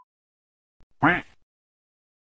cua de pato
cua-de-pato.mp3